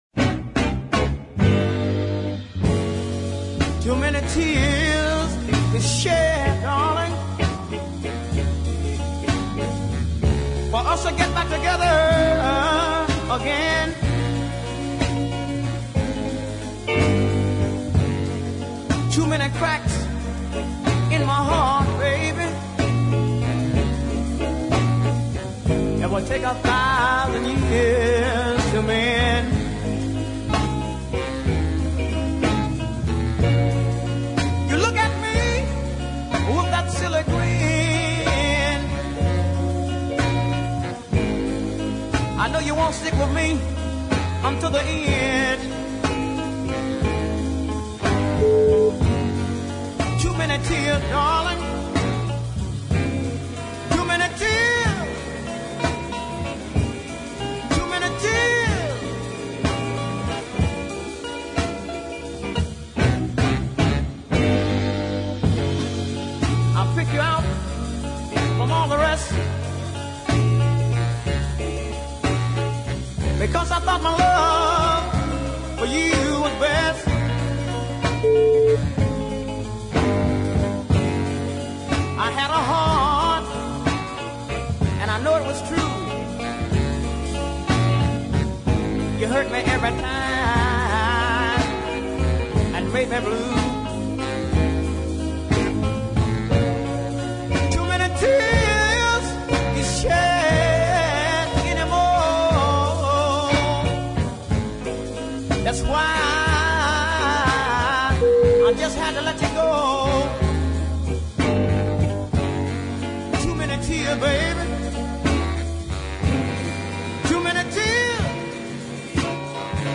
Most of these are R & B based soul
which had some nicely judged chord changes